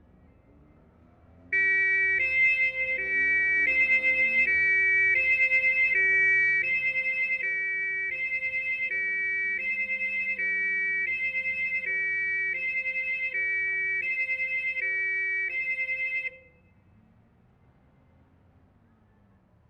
Amsterdam, Holland April 24/75
8. " " " " new model, quite a bit louder, note recordist changed levels.